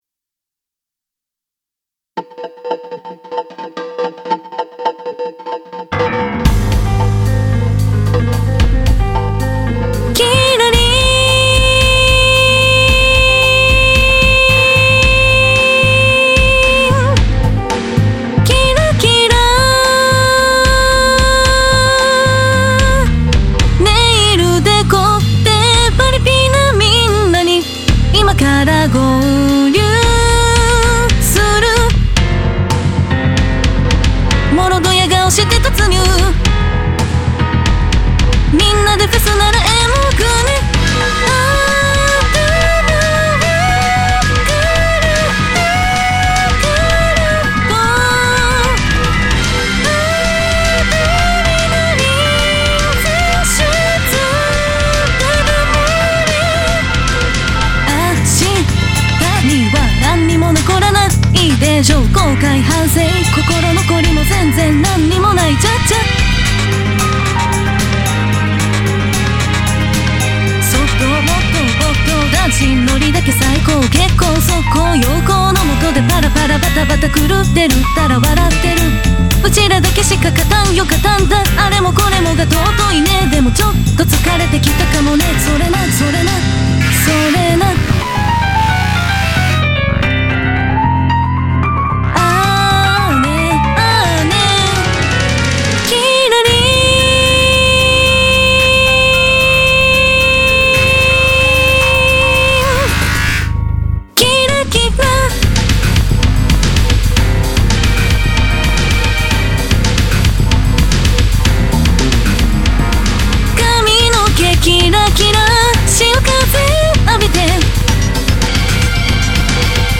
musique ... composition > 声楽曲
Vocal: Synthesizer V "Saki AI"
ボーカルは下のC#からハイF#まで要求されるので、ソプラノでないときついかもしれない。